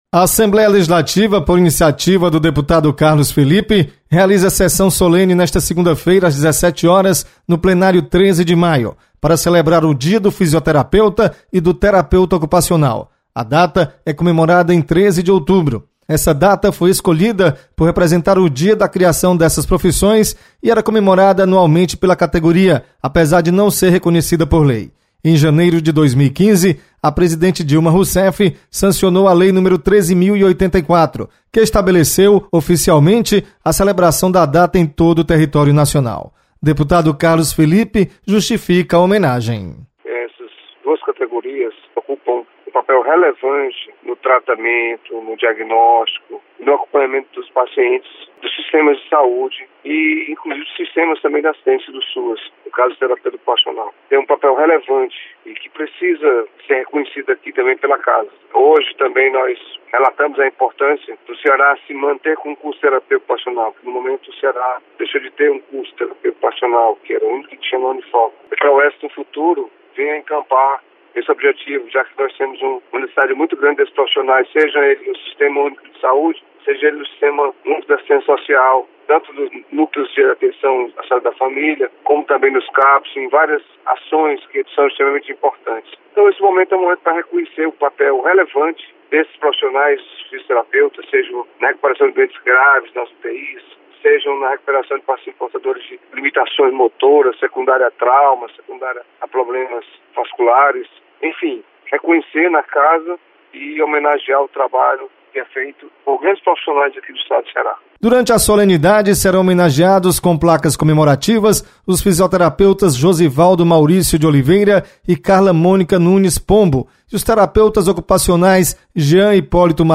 Sessão solene homenageia o Dia do Fisioterapeuta. Repórter